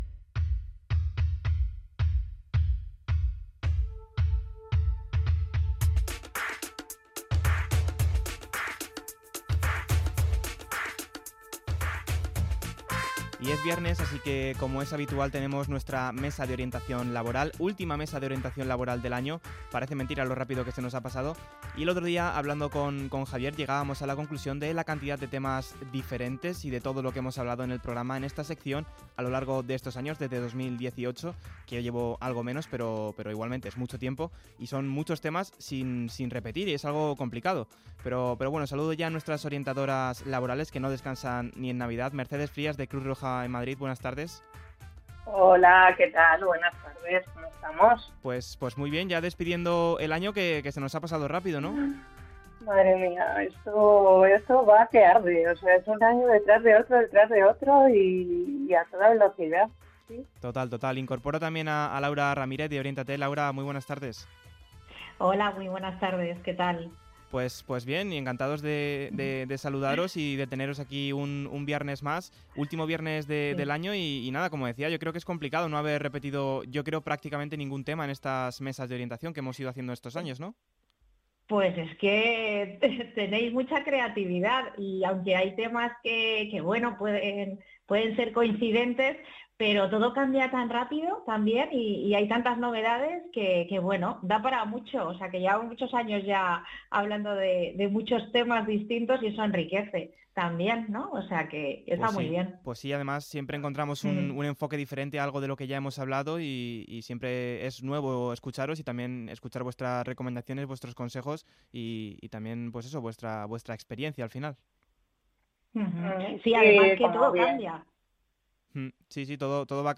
Mesa de Orientación Laboral